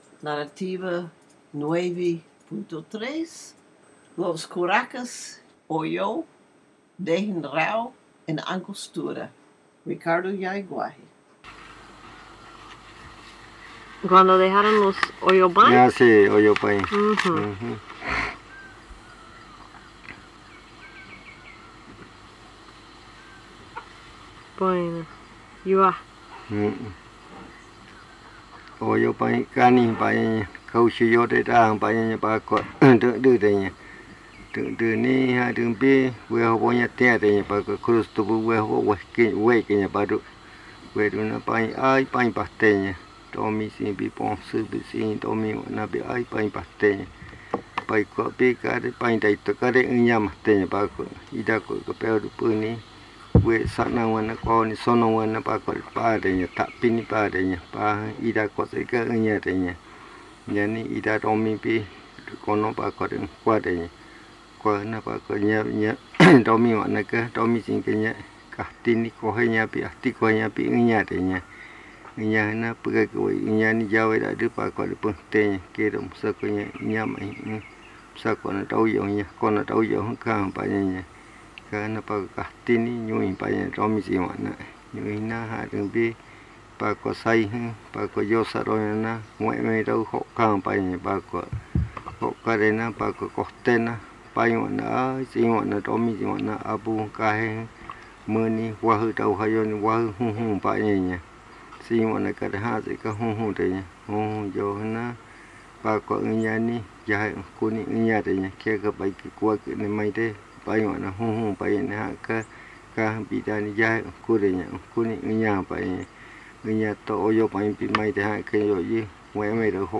Buenavista, río Putumayo (Colombia)